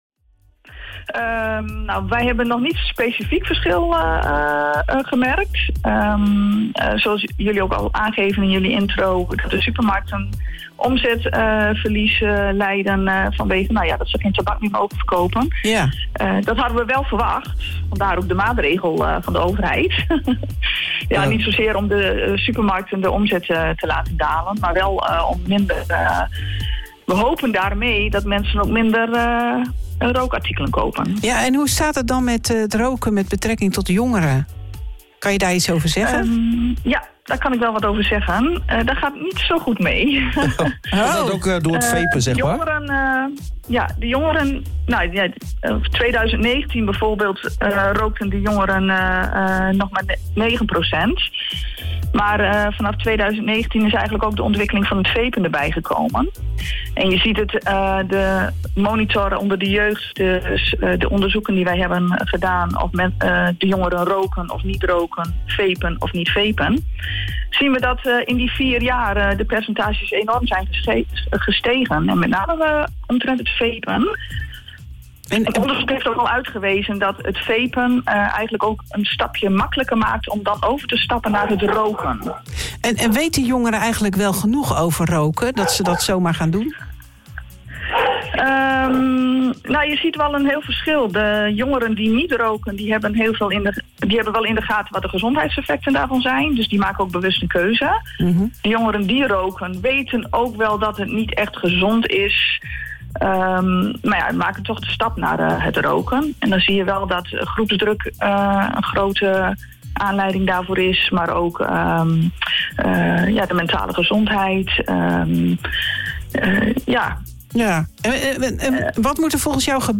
in het OOG Radioprogramma